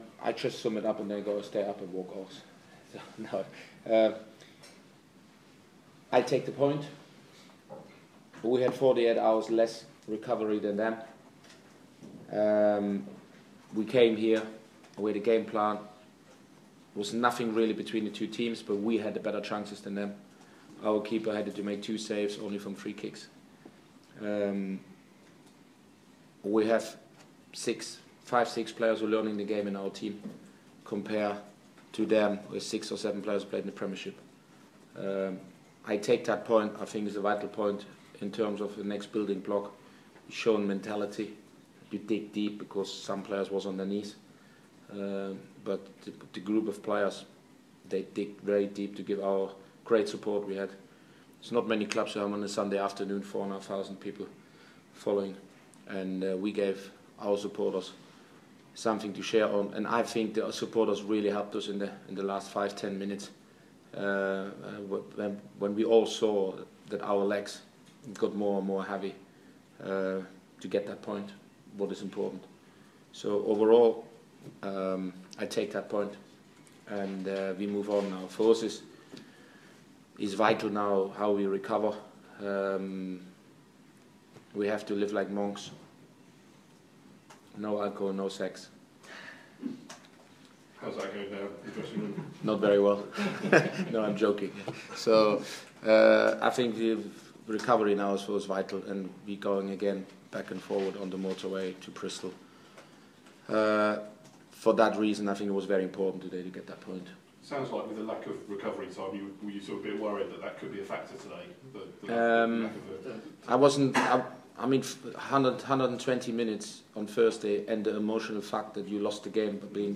Leeds United picked up a second straight draw in the Championship on Sunday, with a 0-0 draw away at Reading. Here's the reaction from boss Uwe Rosler after the game.